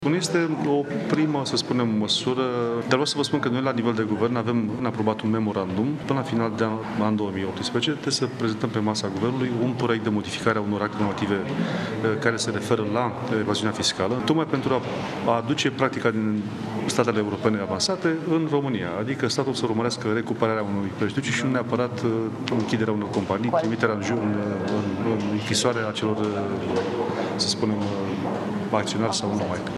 Ministrul Finanțelor, Eugen Teodorovici, a spus, după ședința din Comisie, că asemenea schimbări se înscriu în practica europeană.
09oct-12-teodorovici-despre-evaziune.mp3